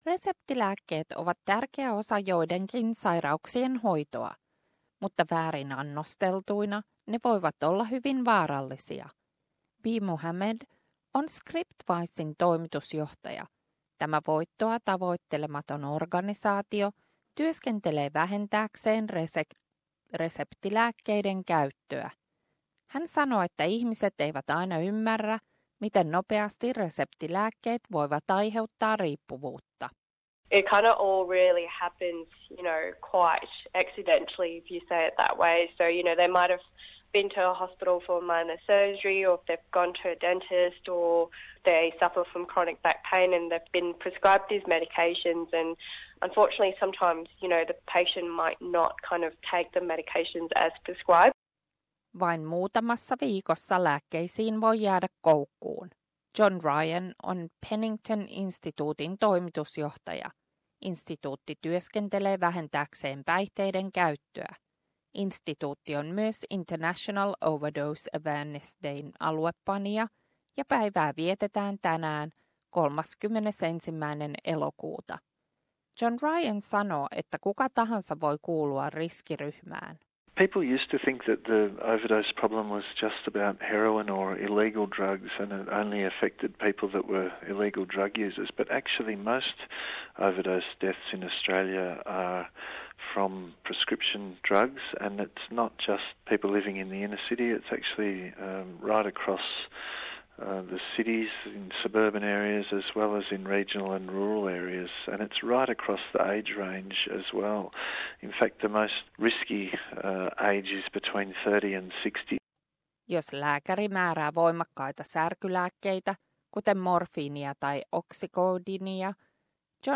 Tieto siitä, että suurin osa Australiassa tapahtuvista yliannostuksista johtuu reseptilääkkeistä eikä laitomista huumeista saattaa yllättää. Reseptilääkkeiden väärinkäyttö on uhka terveydelle, tässä raportti.